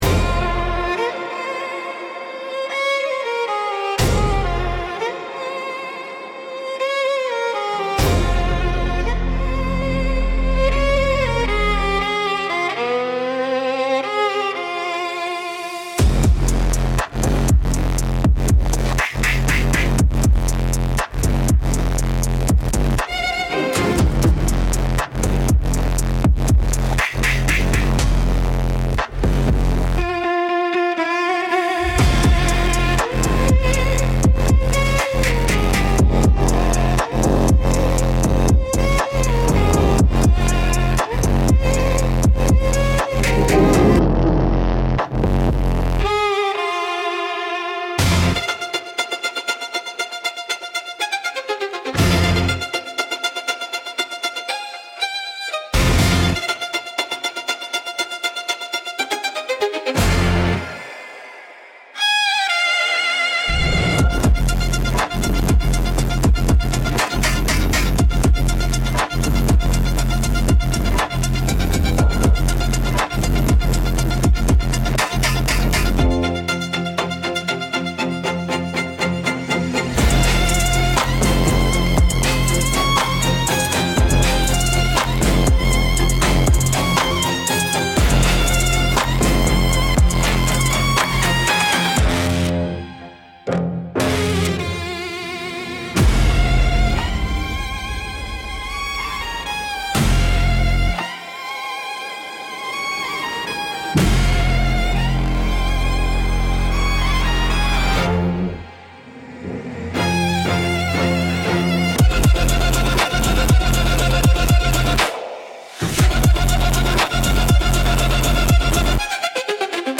Instrumental - Southern Gothic x Dark Pop Fusion